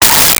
Space Gun 14
Space Gun 14.wav